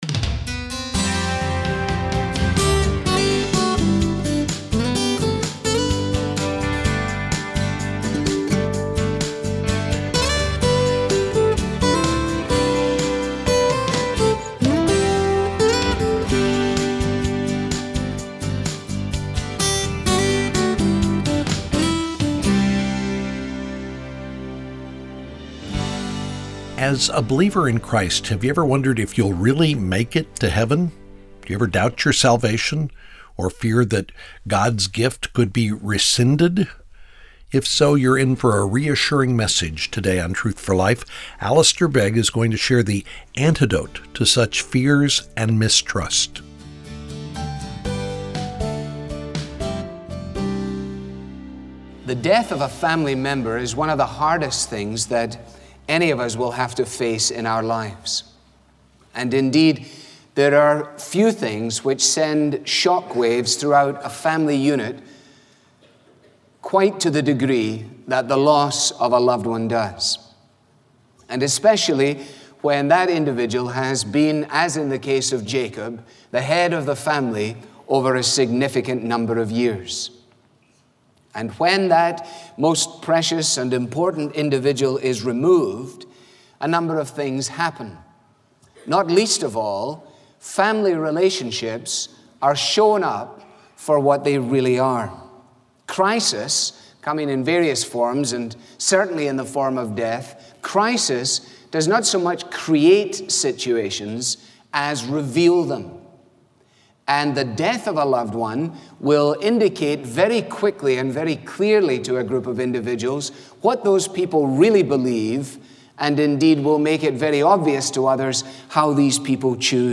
clear, relevant Bible teaching